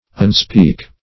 Search Result for " unspeak" : The Collaborative International Dictionary of English v.0.48: Unspeak \Un*speak"\, v. t. [1st pref. un- + speak.]